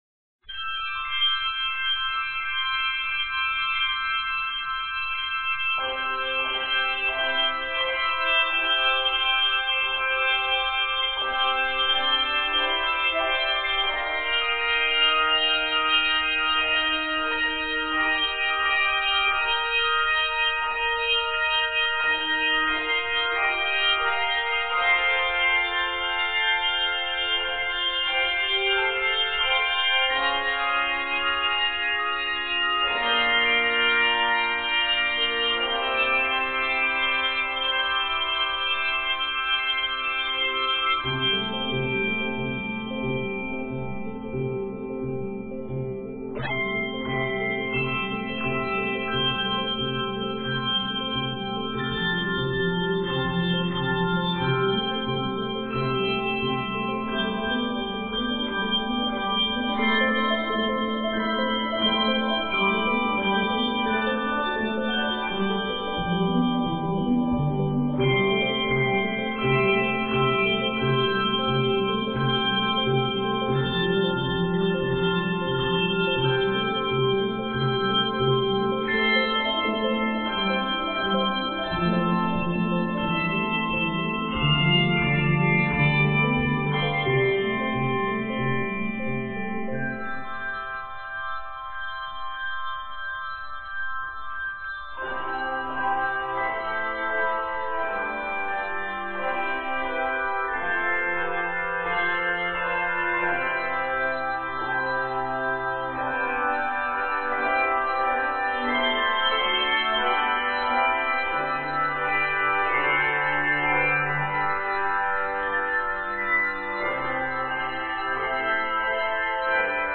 In the style of an organ toccata